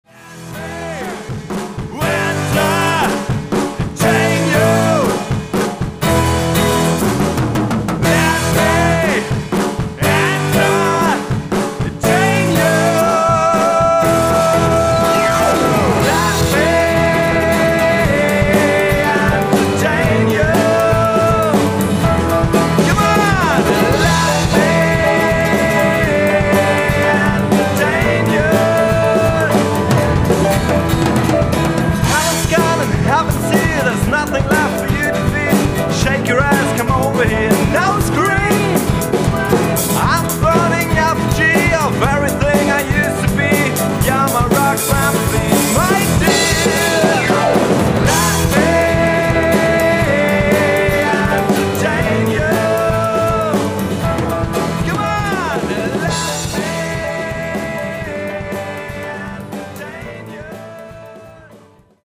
Keyboard
Schlagzeug
Gitarre